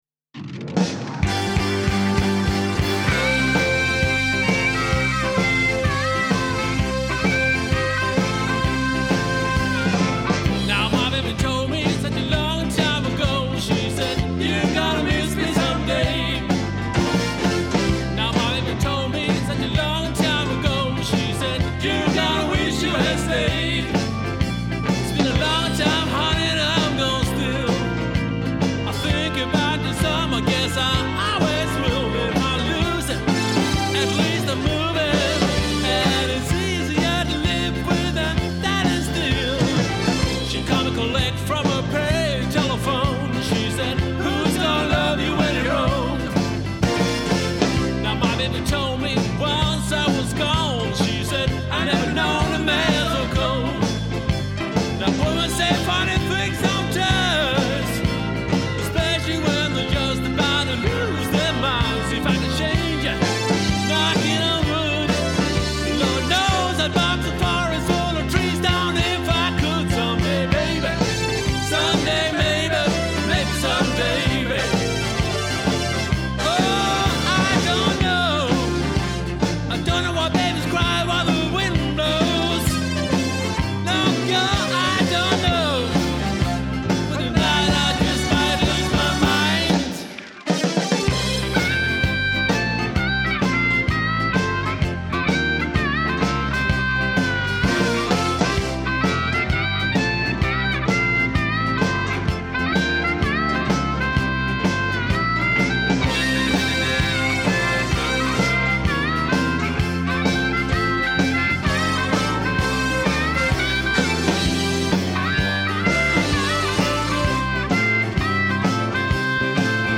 Göteborgs bluesrock: kraftfull musik för alla tillfällen.
Bandet består av 5 medlemmar
• Coverband
• Bluesband